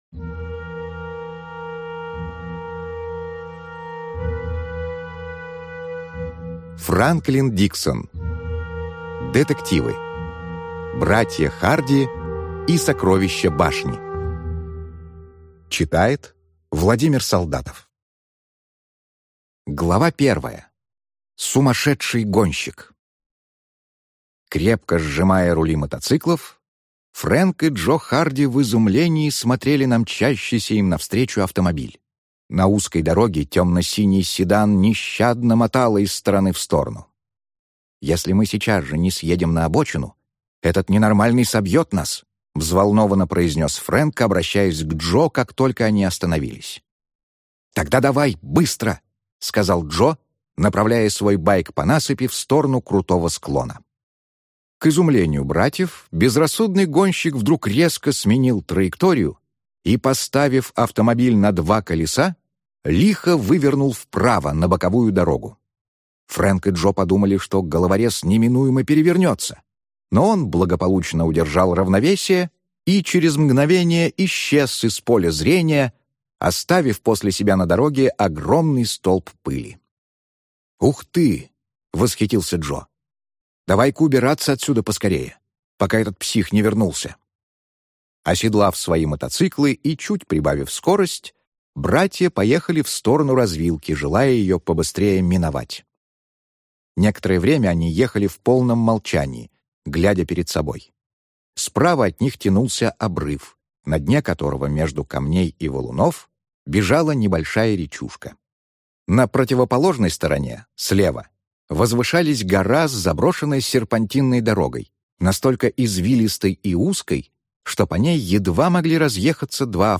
Аудиокнига Братья Харди и сокровища башни | Библиотека аудиокниг
Прослушать и бесплатно скачать фрагмент аудиокниги